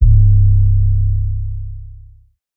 BWB UPGRADE3 COMPRESSED 808 (6).wav